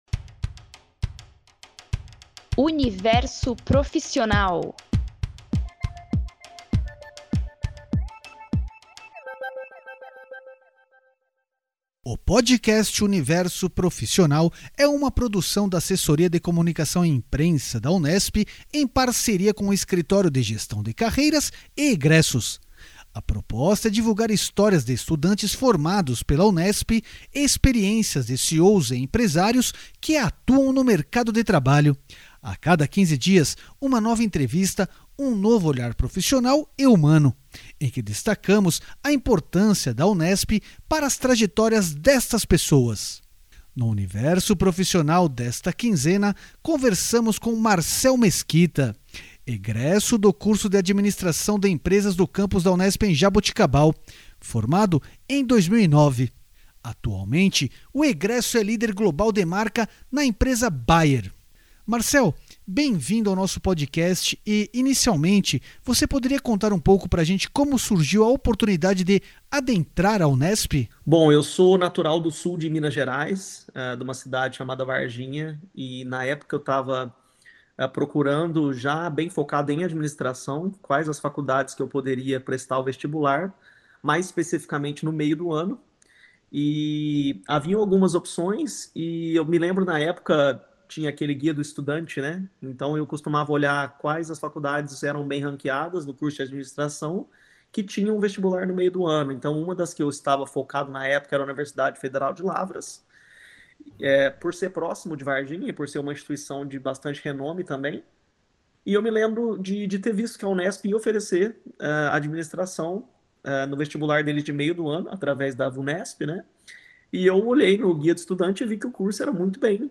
A cada quinze dias, uma nova entrevista e um novo olhar profissional e humano, em que destacamos a importância da Unesp para as trajetórias destas pessoas.